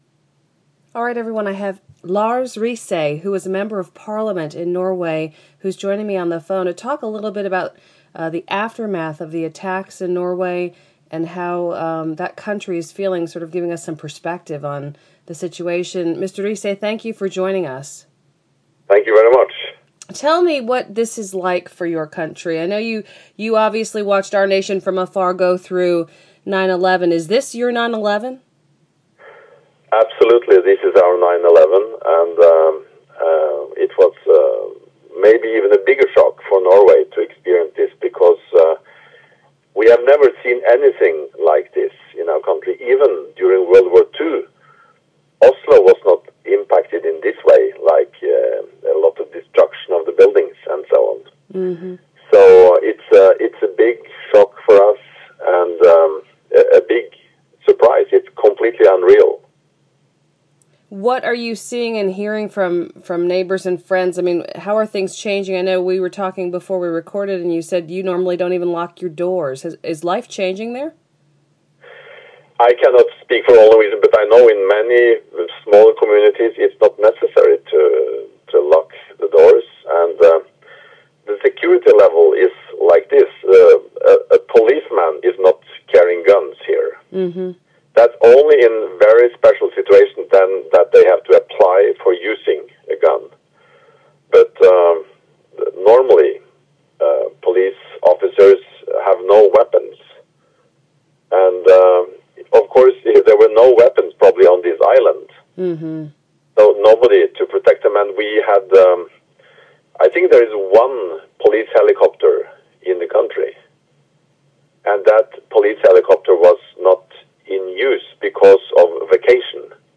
Norway Attacks - former member of parliament Lars Rise (part 1)